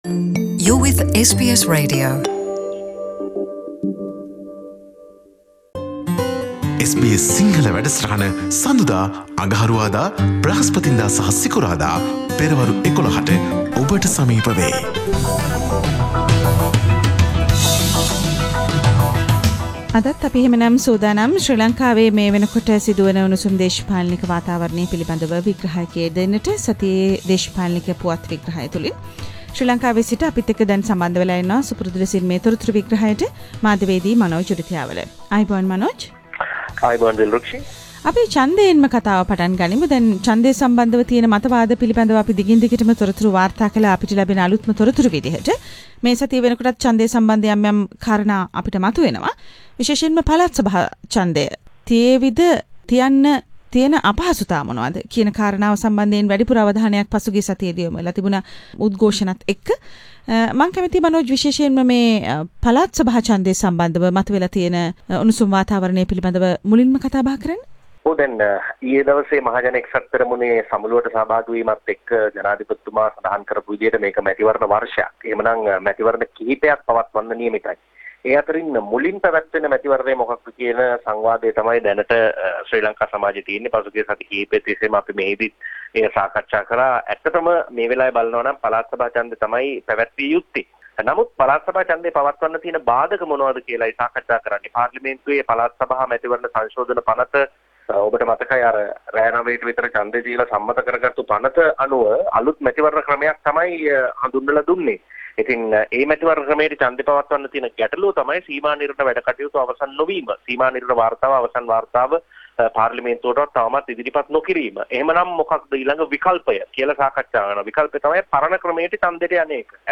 සතියේ දේශපාලන පුවත් සමාලෝචනය